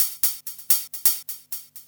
Index of /VEE/VEE Electro Loops 128 BPM
VEE Electro Loop 166.wav